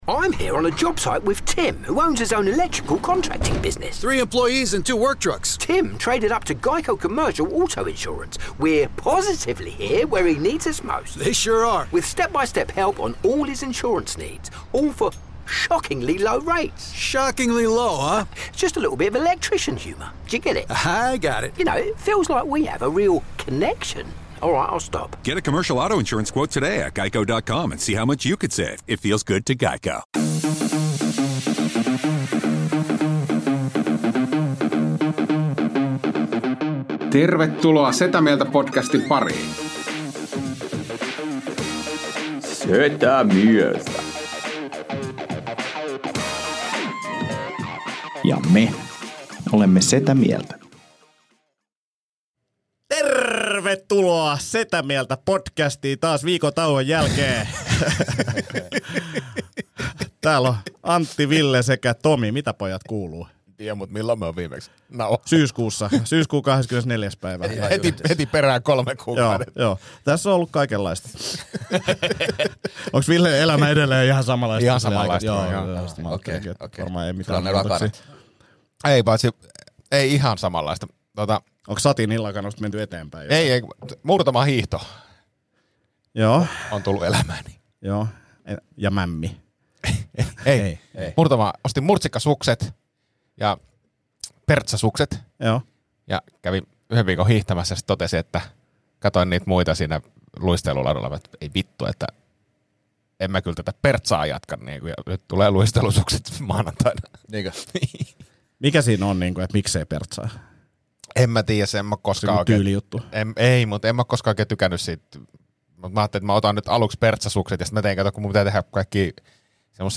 keski-ikäisiä, itselleen yllättävän setäisiä miehiä